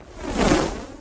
throw_jack_o_lantern.ogg